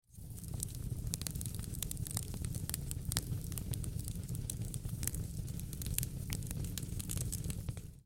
Modele AI generują efekty dźwiękowe na podstawie wprowadzonego promptu.
Oto kilka efektów dźwiękowych, które stworzyłem, wraz z ilustracjami przedstawiającymi ich źródła.
Ognisko
fire.mp3